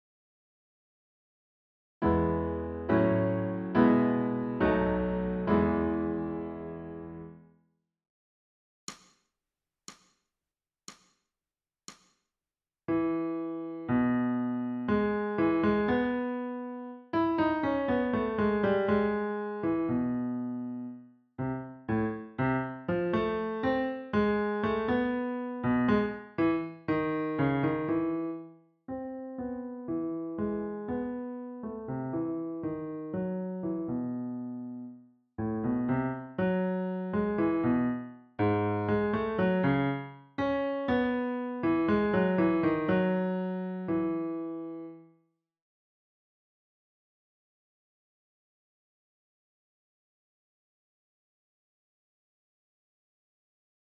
ソルフェージュ 聴音: 2-1-40